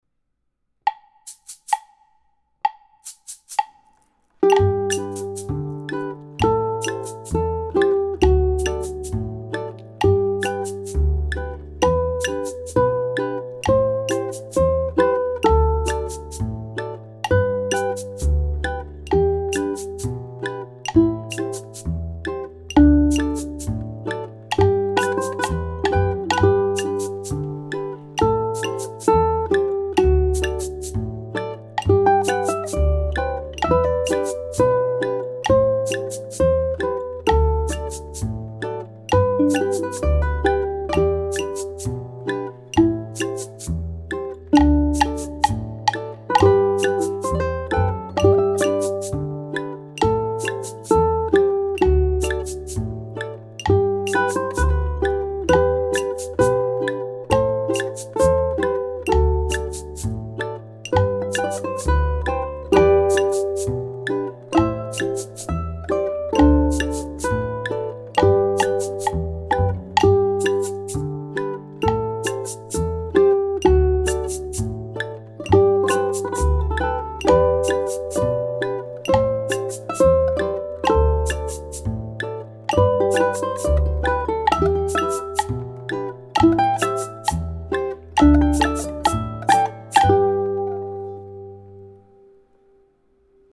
The mellow Christian hymn
The Reggae strum
ʻukulele